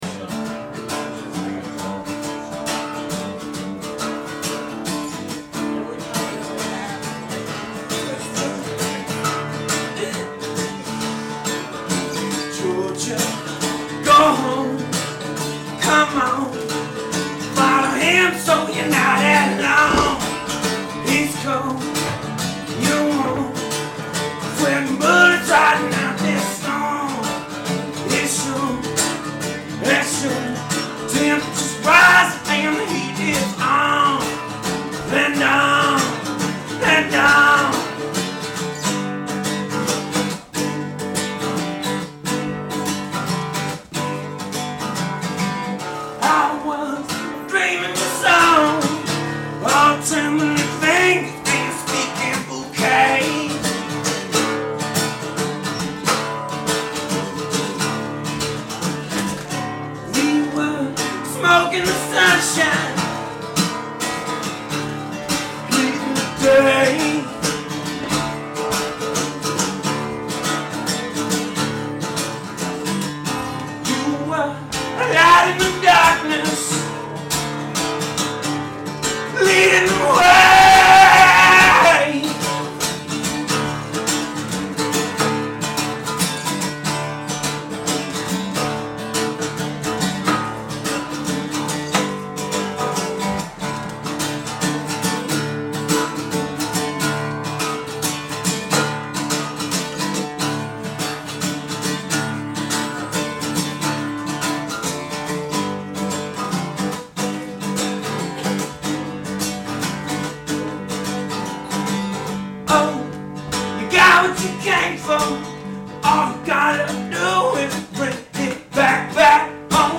Live WGXC Online Radio broadcast.